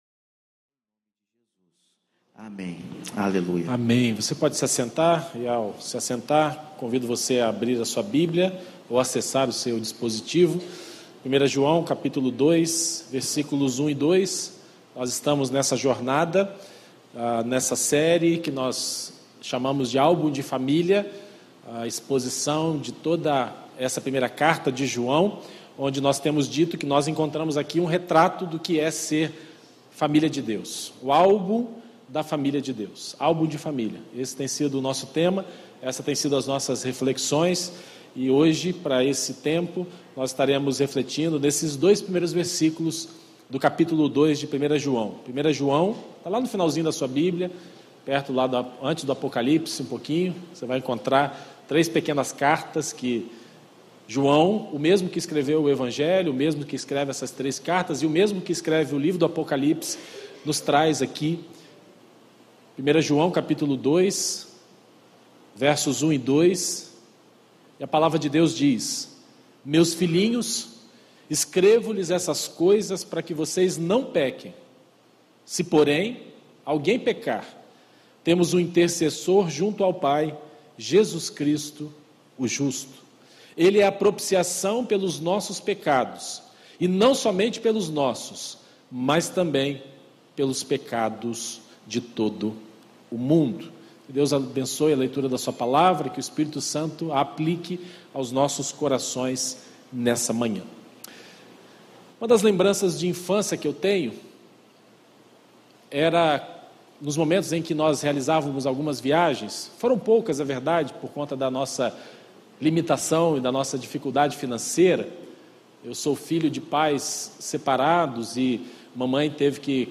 Exposições em 1º João